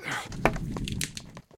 fireplace_wood.ogg